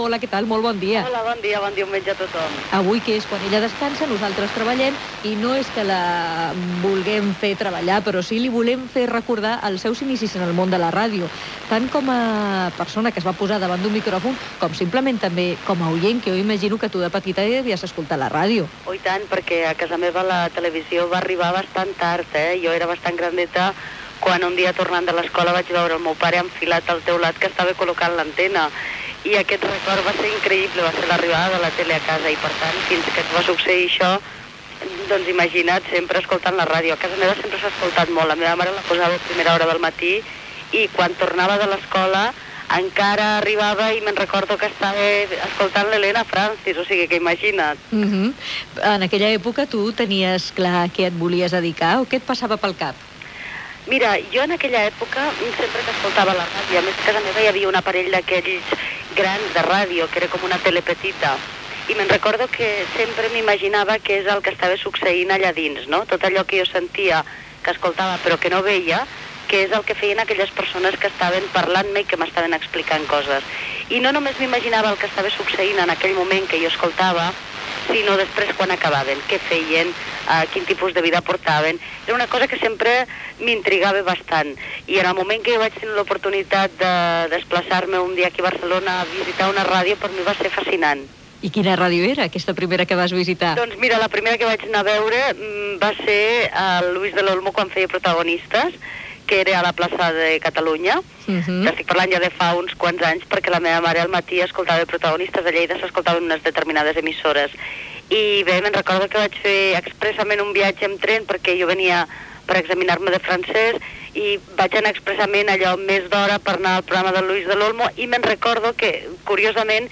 Entrevista a la presentadora Mari Pau Huguet sobre els seus primers records de la ràdio i la televisió i la seva feina professional